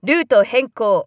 明らかに怒ってますニャ！
飼い主２：「なんか、萌え系の音声も入ってる！